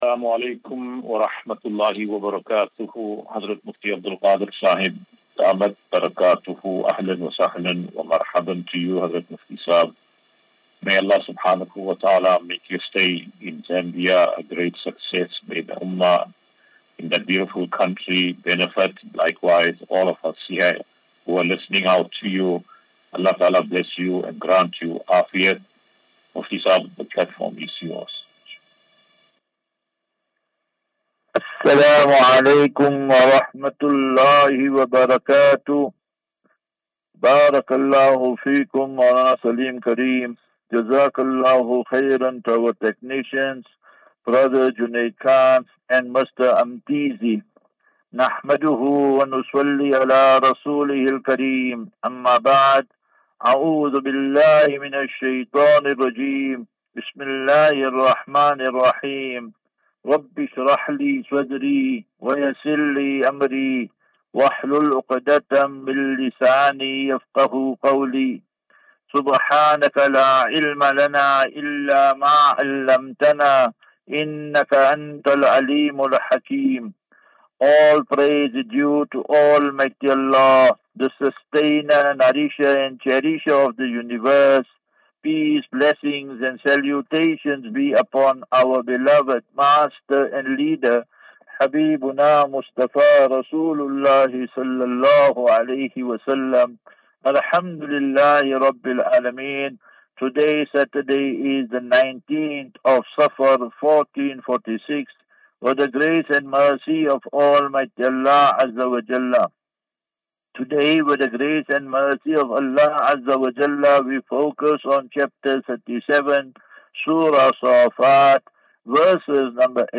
24 Aug 24 August 2024. Assafinatu - Illal - Jannah. QnA